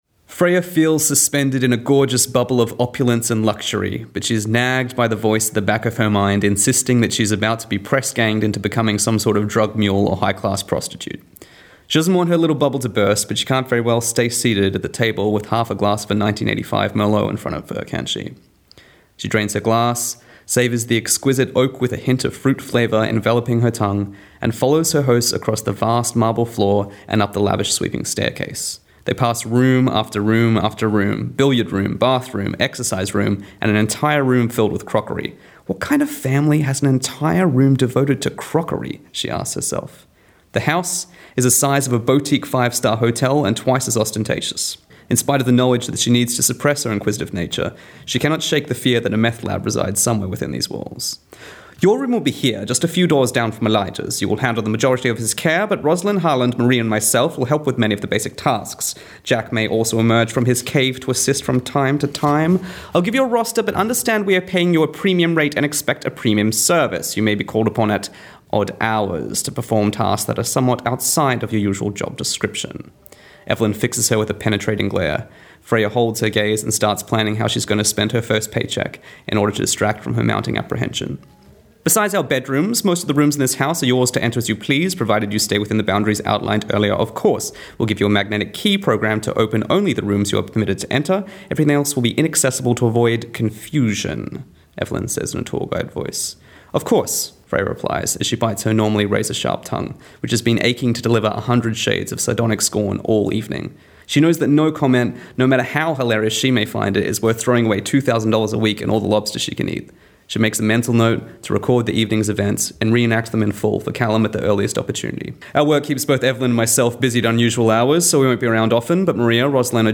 Recorded at the Bellingen Writers Festival 2018